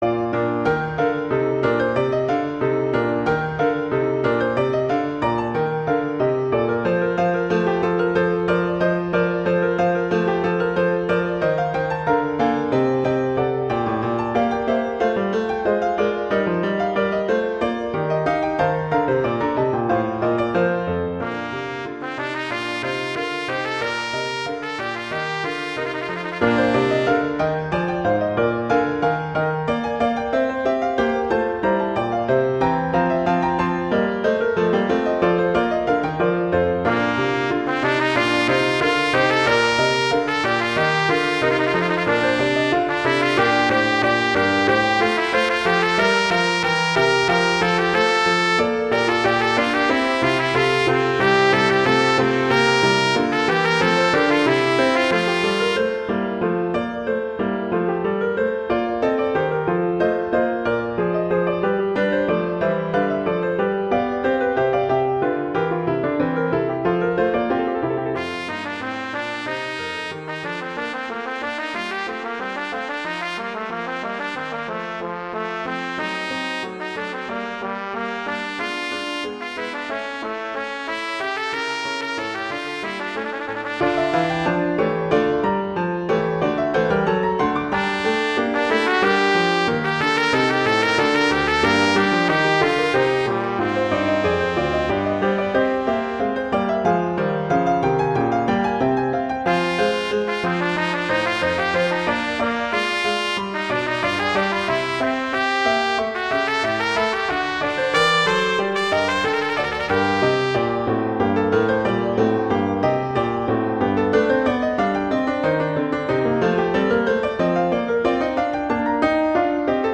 Instrumentation: trumpet & piano
transcription for trumpet and piano
classical, concert
Bb major
♩=60-114 BPM (real metronome 60-112 BPM)